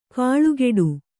♪ kāḷugeḍu